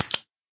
flop4.ogg